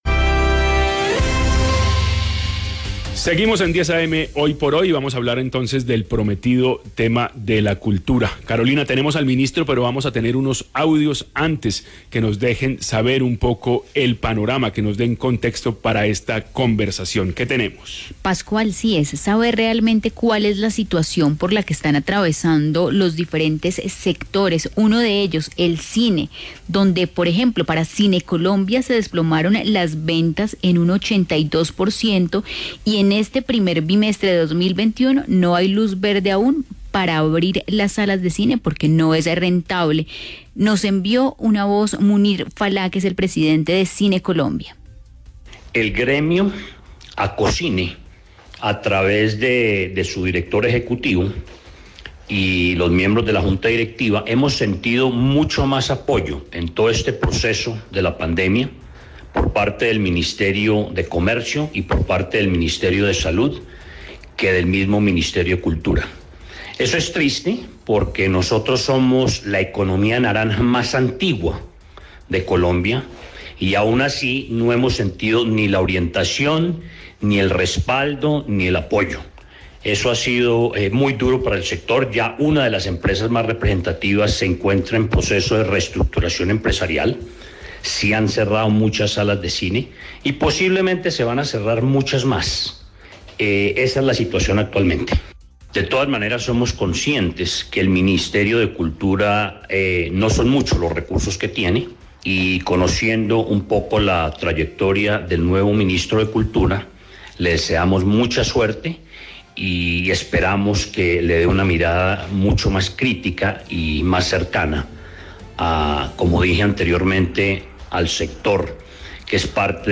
En entrevista con Hoy por Hoy 10 a.m. de Caracol Radio, Felipe Buitrago destacó el trabajo interinstitucional con nuestra Universidad, dentro del cual se encuentran diplomados, conversatorios y publicaciones.
El ministro fue entrevistado en el programa Hoy por Hoy 10 a.m. de Caracol Radio. Allí mencionó los retos que ha dejado la pandemia y cómo se pueden enfrentar las transformaciones en el largo plazo.